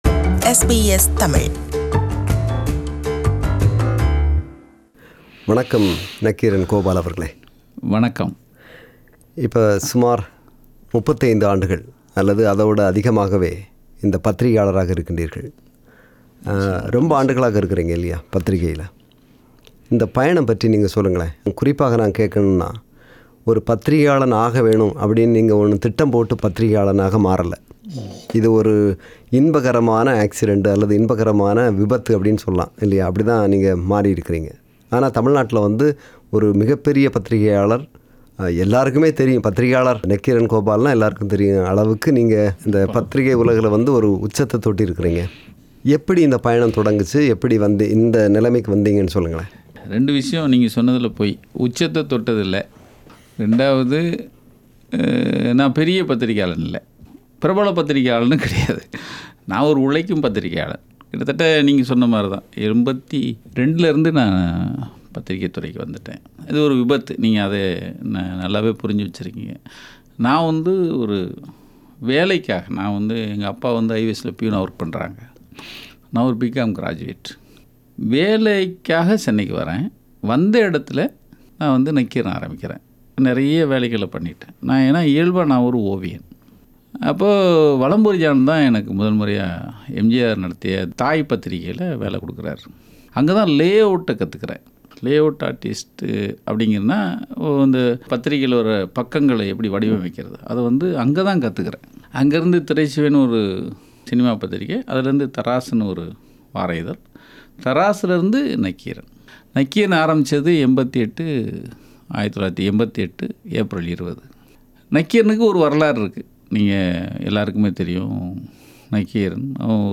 Interview with Nakkheeran Gopal – Part 1